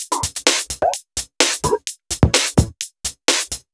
Index of /VEE/VEE2 Loops 128BPM
VEE2 Electro Loop 324.wav